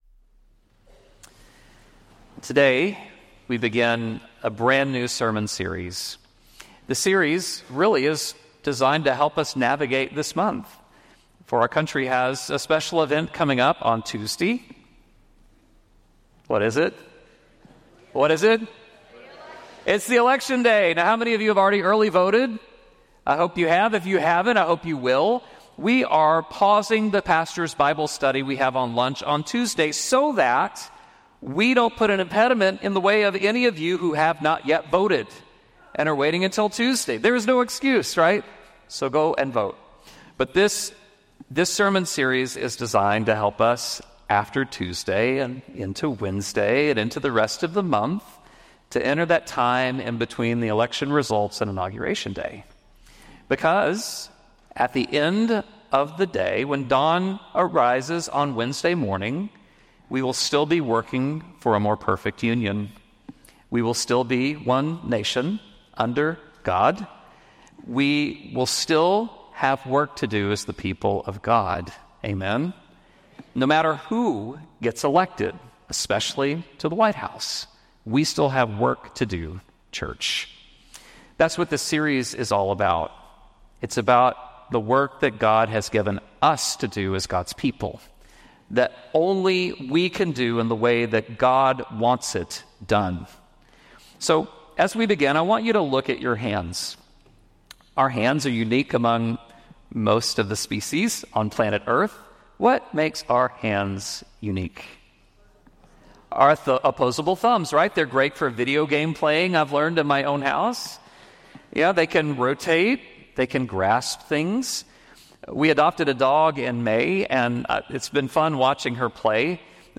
Mark 12:28-34 Service Type: Traditional https